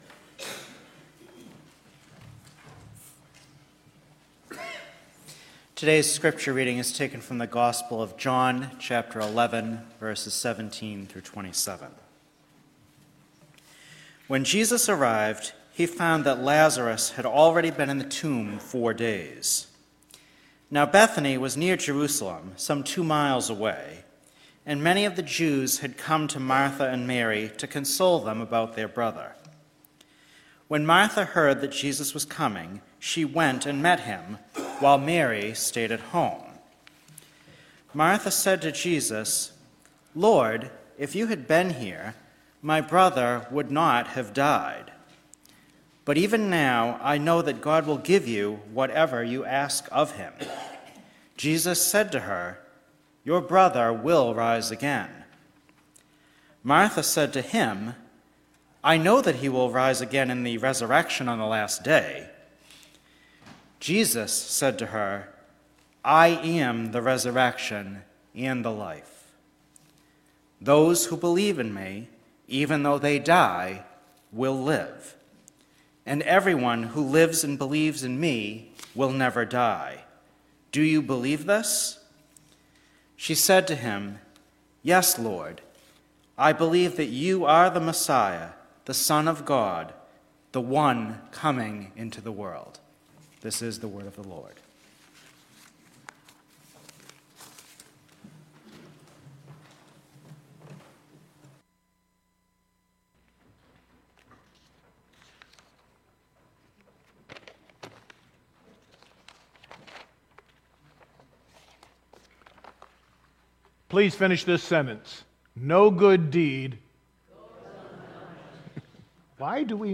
ScriptureReading-and-Sermon-Oct.-1-2023.mp3